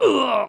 damage_3.wav